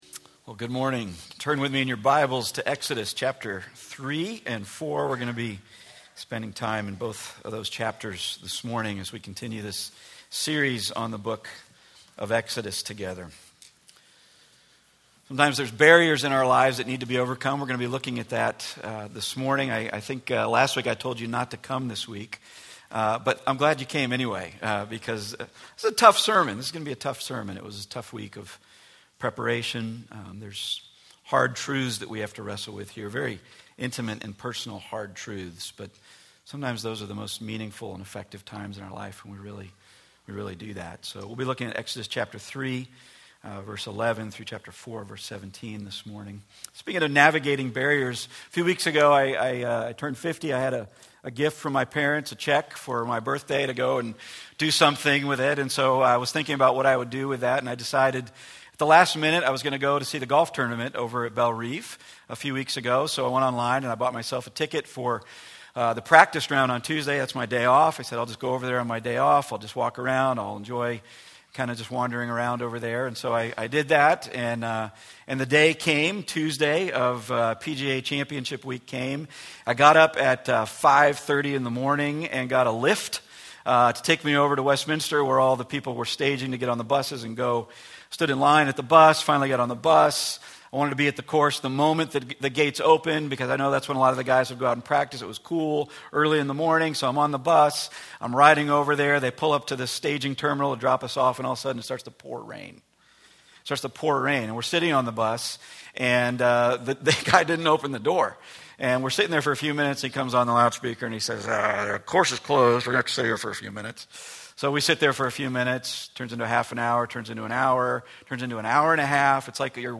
Exodus 3:11-4:17 Service Type: Weekly Sunday Part 5 of a series in the book of Exodus